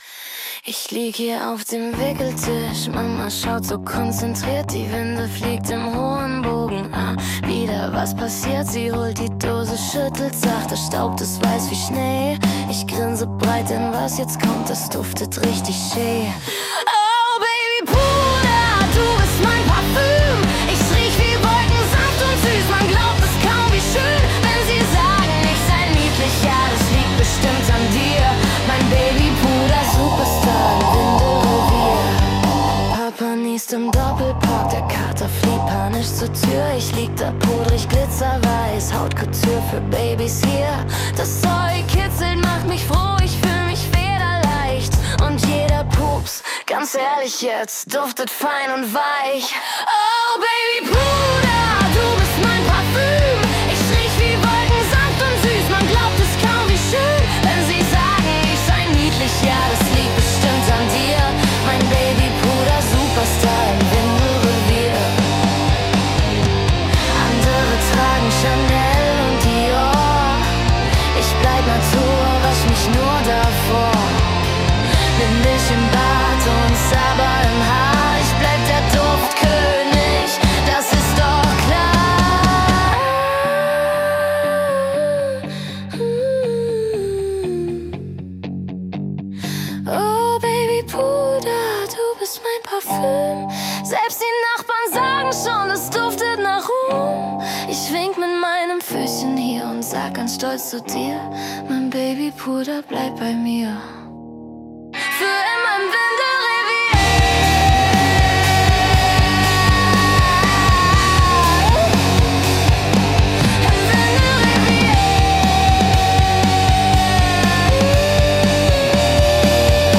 „Die Wolke“ ist ein humorvoller, verspielter Song, der mit einem liebevollen Augenzwinkern das Thema Babypuder feiert – ein Symbol für Reinheit, Geborgenheit und kindlichen Charme.